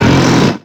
Cri de Groret dans Pokémon X et Y.